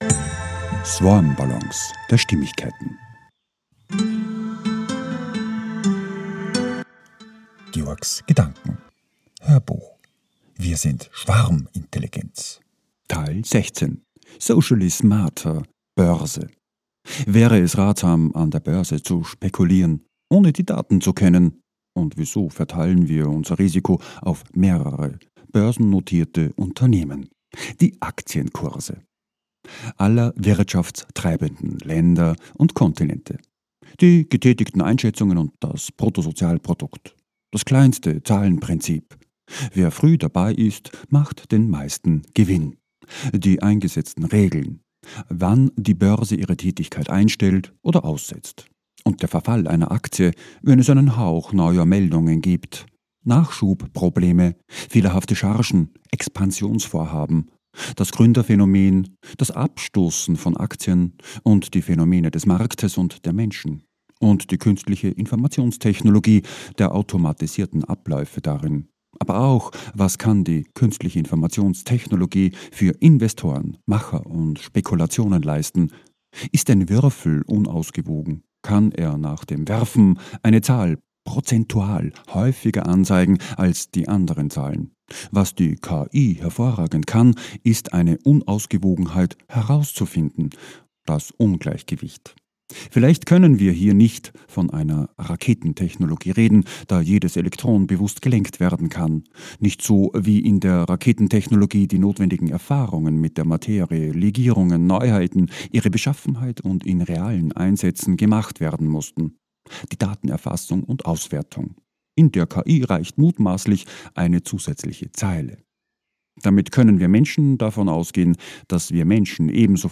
HÖRBUCH - 016 - WIR SIND SCHWARMINTELLIGENZ - Socially SMARTER - BÖRSE